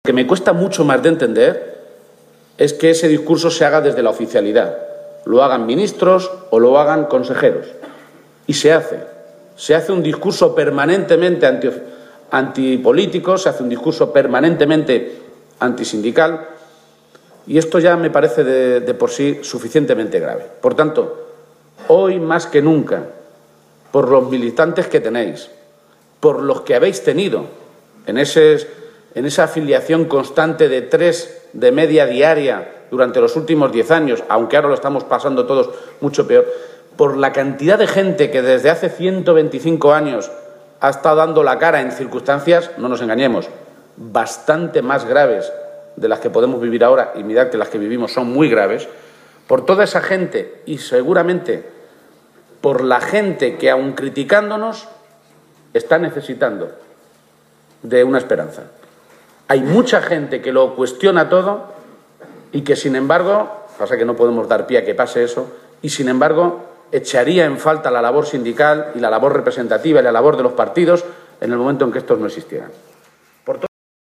García-Page ha hecho estas consideraciones durante su intervención en el acto inaugural del VI Congreso Regional del sindicato UGT celebrado en Toledo, donde el líder de los socialistas castellano-manchegos ha afirmado que todos los indicadores sobre la situación actual de la Región «reflejan una realidad verdaderamente grave en Castilla-La Mancha», tras la política de recortes de los gobiernos de Cospedal y Rajoy.